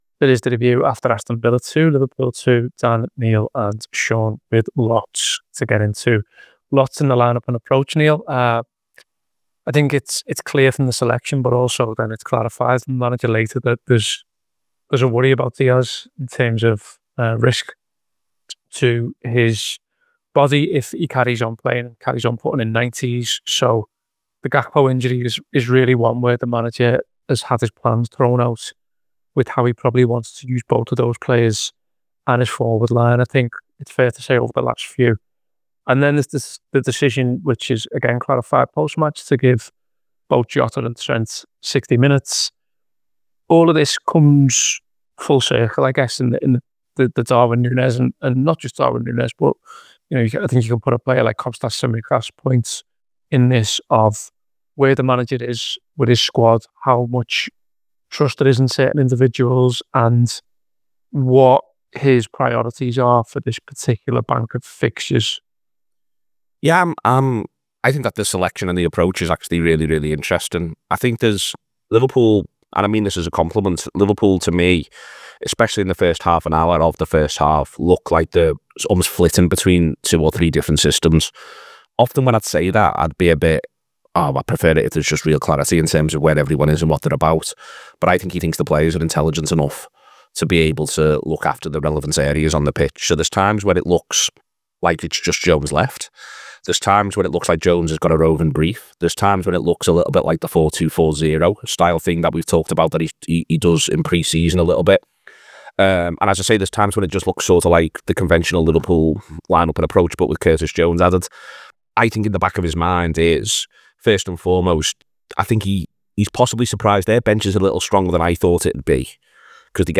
Below is a clip from the show – subscribe to The Anfield Wrap for more review chat around Aston Villa 2 Liverpool 2…